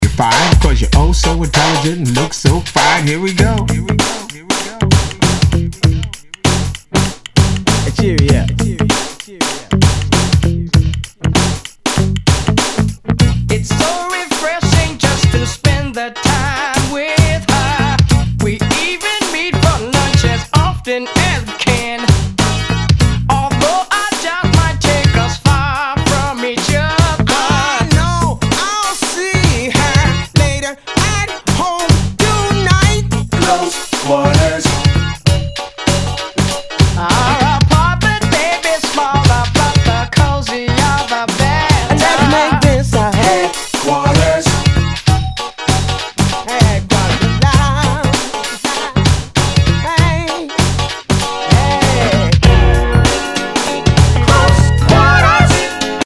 HIPHOP/R&B
ナイス！R&B/ ファンク！